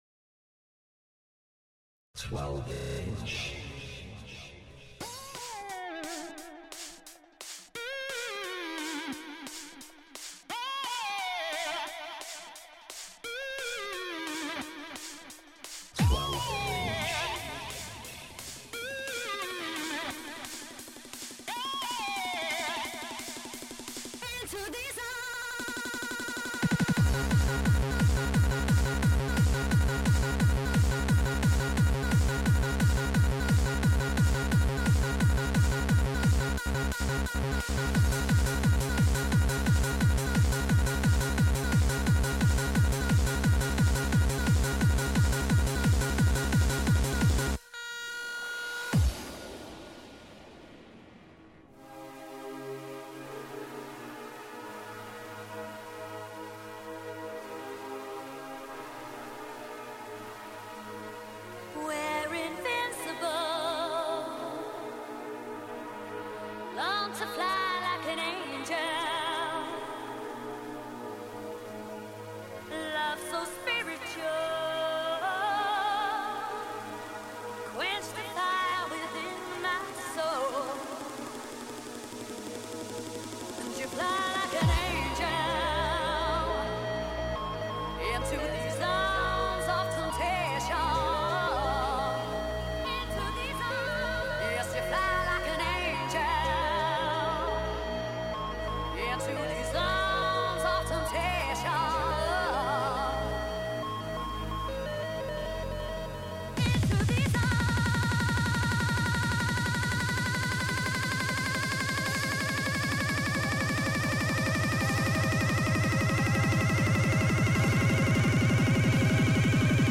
4 to the floor all the way